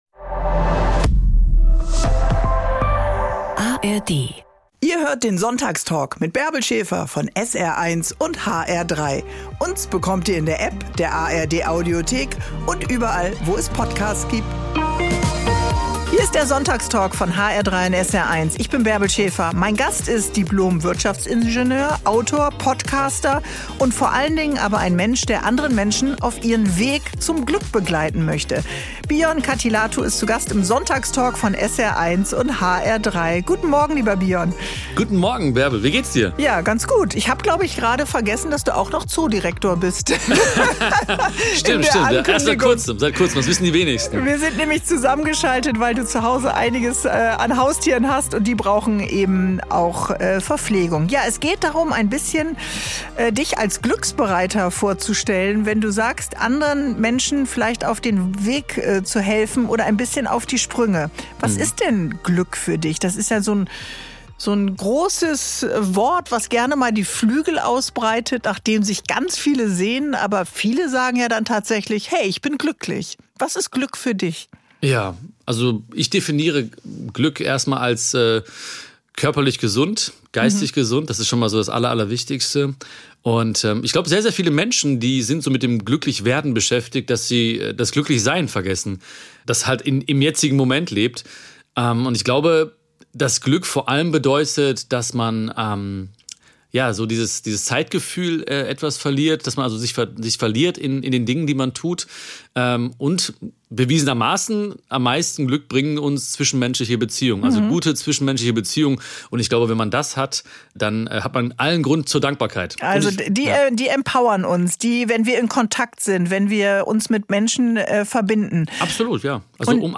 Im Sonntagstalk von SR 1 und hr3 gibt er Tipps, wie wir im Alltag Momente der Ruhe einbauen und wertschätzen, was wir bereits haben. Im Gespräch mit Bärbel Schäfer nimmt er uns mit, wie es ihm gelungen ist, das für sein eigenes Leben zu beherzigen und auch für vermeintliche Kleinigkeiten Dankbarkeit zu empfinden.